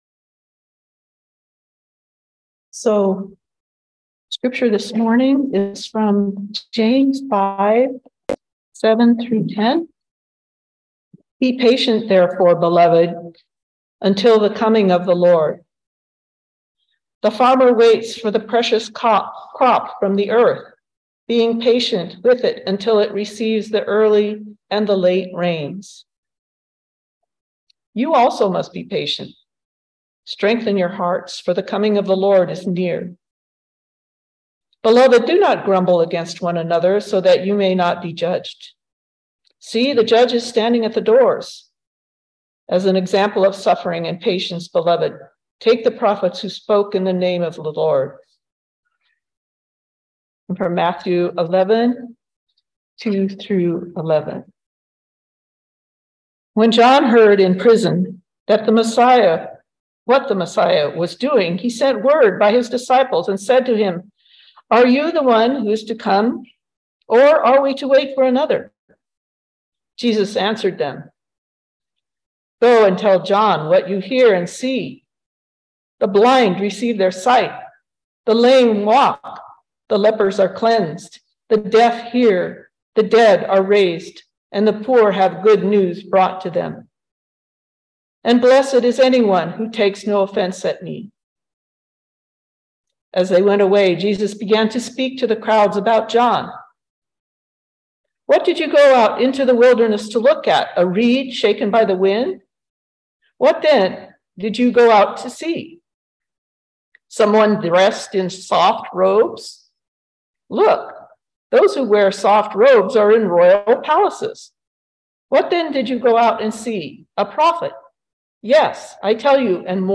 Listen to the most recent message from Sunday worship at Berkeley Friends Church, “Jesus and John.”